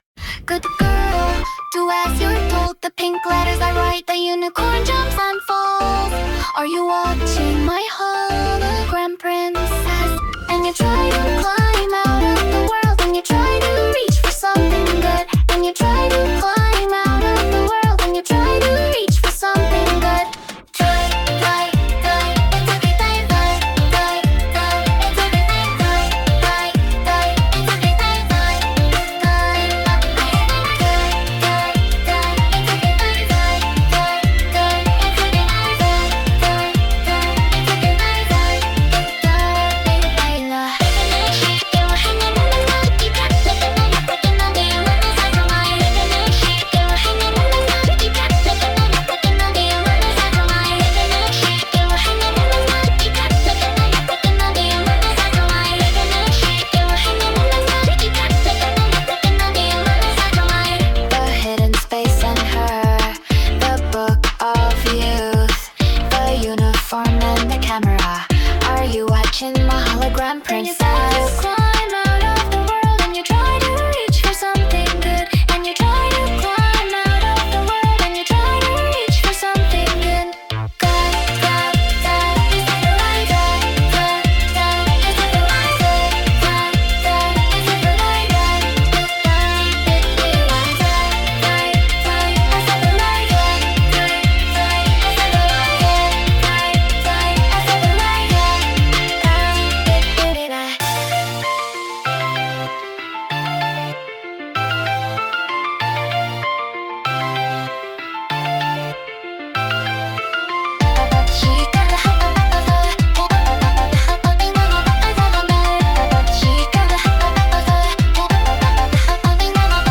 • ジャンル：エラーポップアイドル
• 声：柔らかく、落ち着いたトーン／ささやくような優しさ